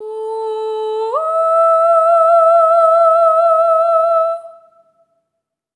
SOP5TH A4 -R.wav